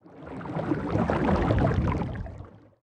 Sfx_creature_glowwhale_swim_fast_02.ogg